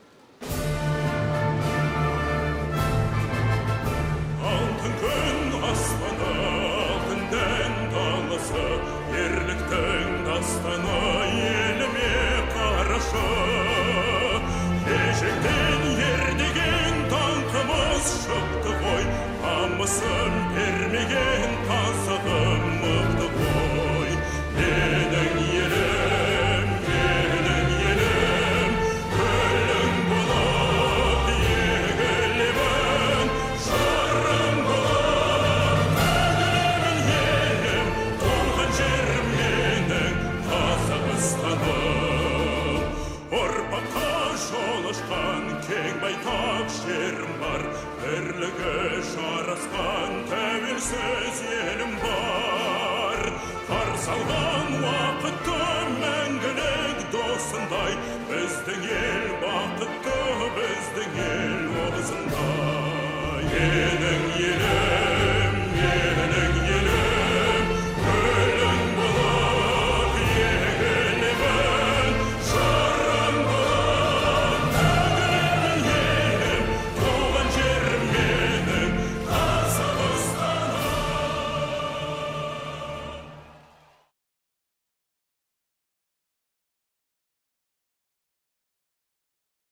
ACTUAL_Kazakh_anthem.mp3